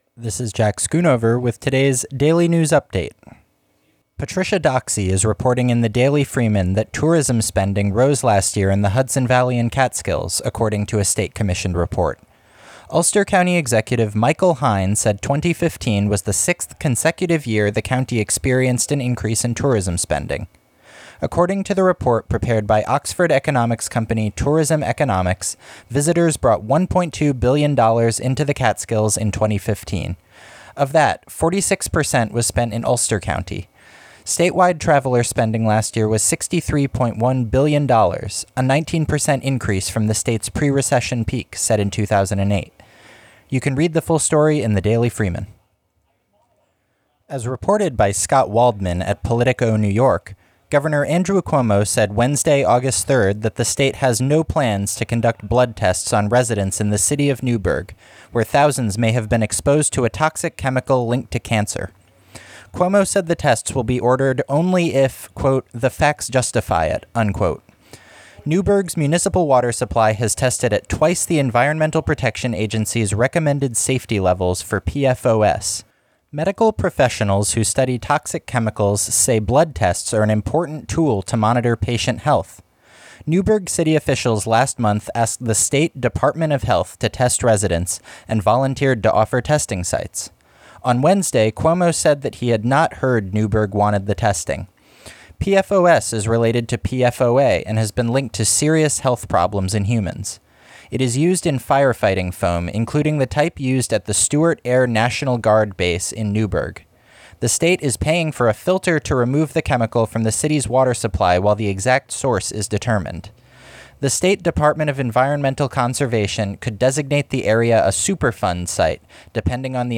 Regional news for Hudson Valley and Capitol Region.